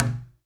Knock26.wav